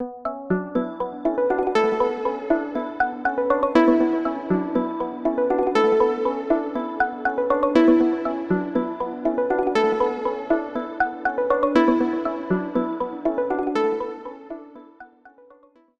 No other processing was done on these sounds other than the onboard effects included with the instrument.
Samples based on resonant objects with plenty of onboard effects and arpeggiation possibilities.